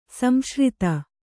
♪ samśrita